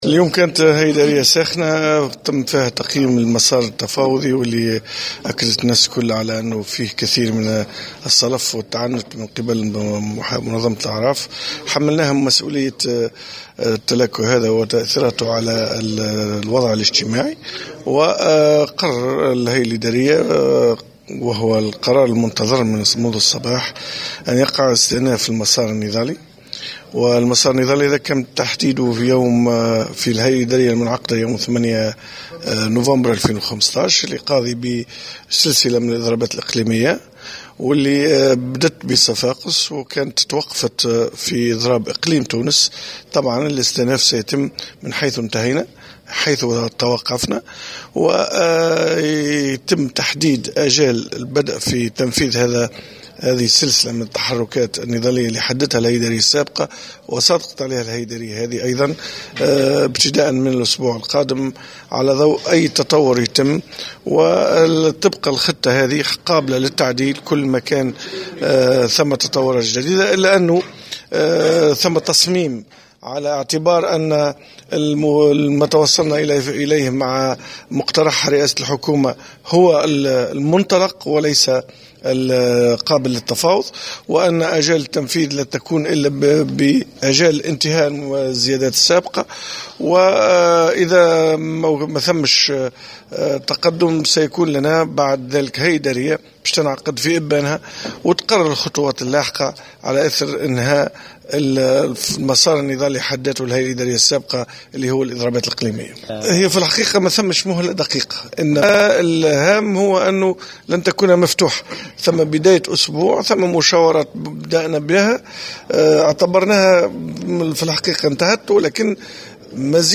في تصريح إعلامي في اختتام أعمال الهيئة الادارية.